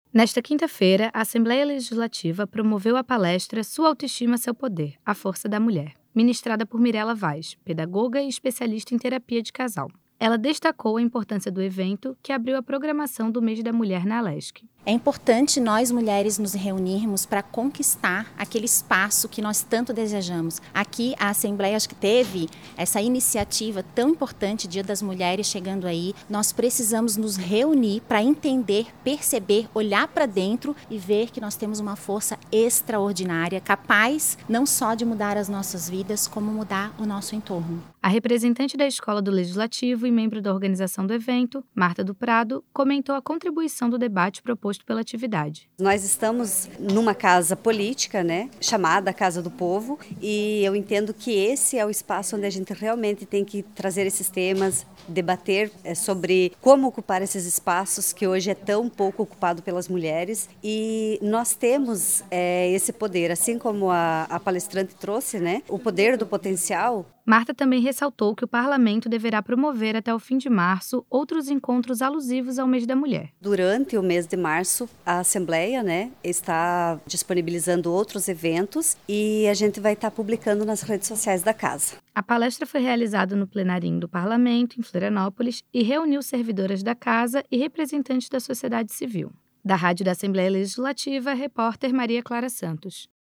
Entrevistas com: